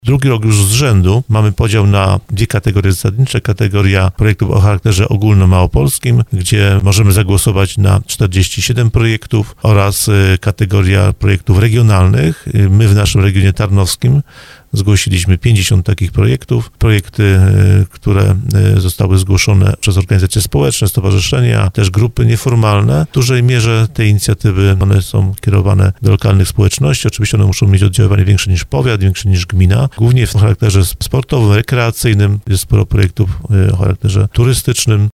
Do głosowania zachęcał w audycji Słowo za Słowo Wojciech Skruch Wiceprzewodniczący Sejmiku Województwa Małopolskiego.